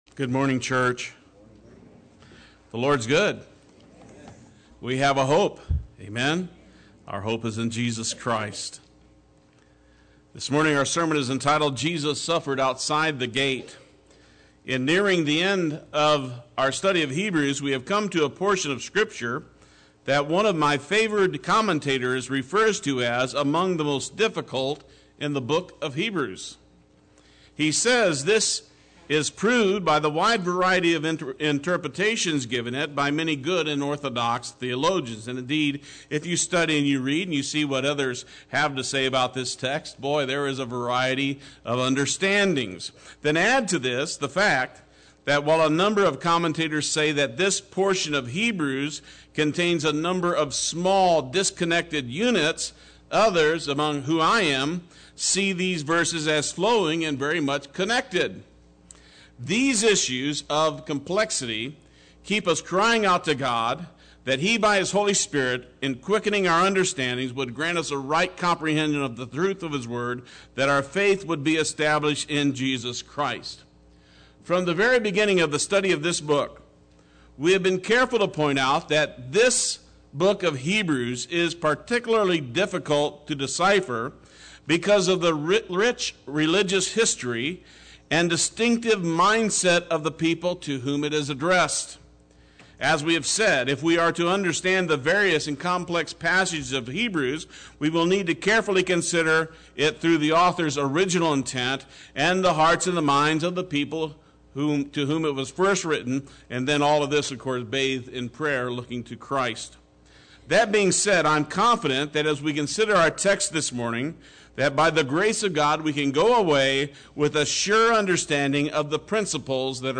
Play Sermon Get HCF Teaching Automatically.
Jesus Suffered…Outside the Gate Sunday Worship